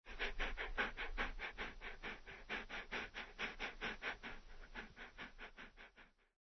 Descarga de Sonidos mp3 Gratis: animal 2.